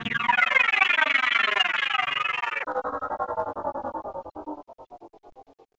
.wav 16 bit 44khz, Microsoft ADPCM compressed, mono,
Gold.wav Chopped up weird string FX 64k